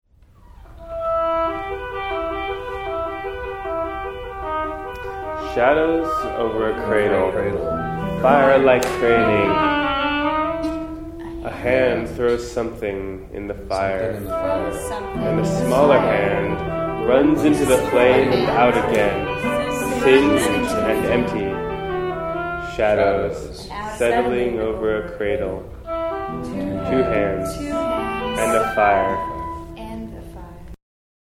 All sound recorded by Parallel Octave on Sunday, September 9, 2012, in the Arellano Theater on the JHU campus.